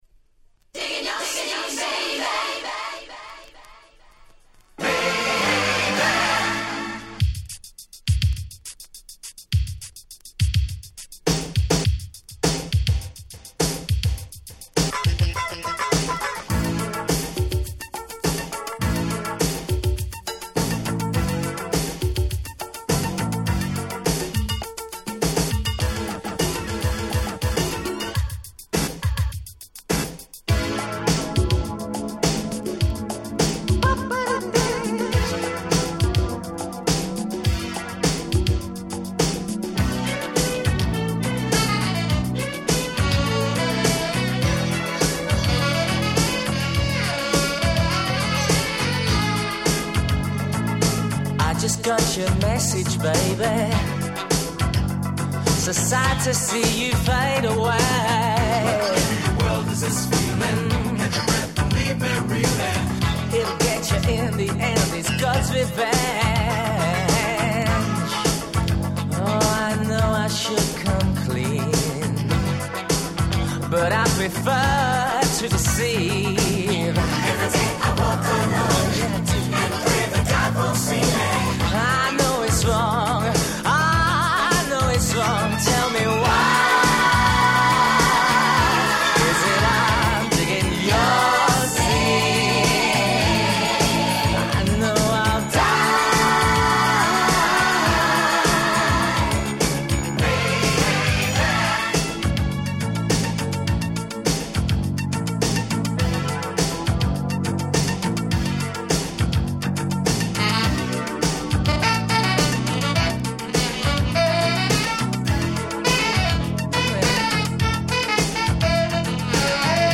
※試聴ファイルは別の盤から録音してございます。
86' Nice UK Disco !!
夏っぽいメロディーが非常に心地良いです！
80's ディスコ キャッチー系